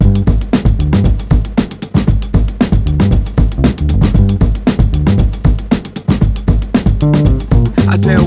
• 116 Bpm Modern Drum Beat G Key.wav
Free drum loop sample - kick tuned to the G note. Loudest frequency: 701Hz
116-bpm-modern-drum-beat-g-key-Gm8.wav